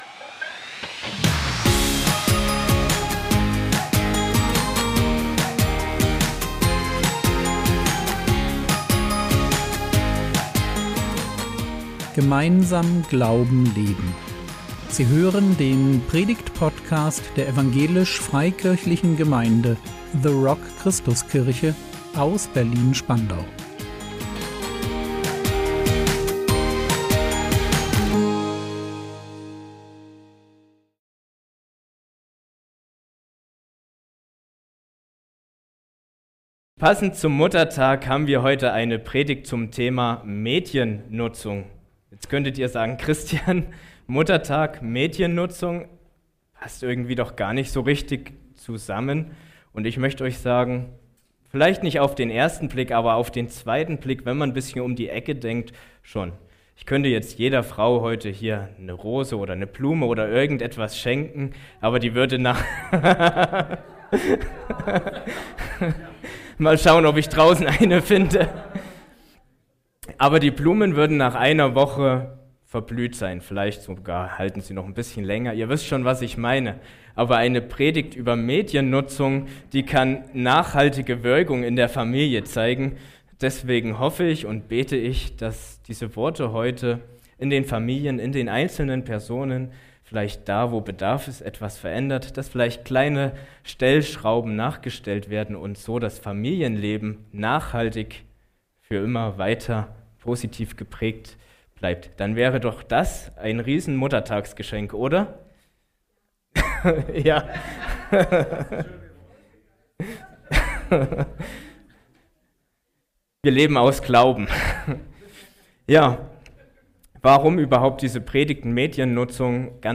Präsent sein im Hier und Jetzt | 12.05.2024 ~ Predigt Podcast der EFG The Rock Christuskirche Berlin Podcast